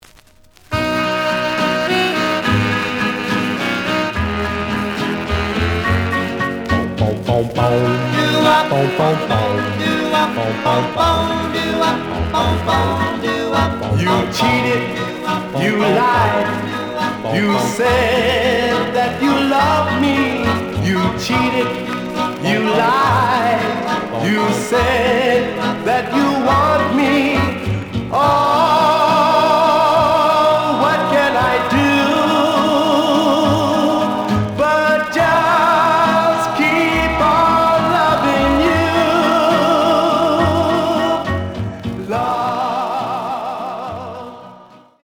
The audio sample is recorded from the actual item.
●Format: 7 inch
●Genre: Rhythm And Blues / Rock 'n' Roll
Slight noise on A side.)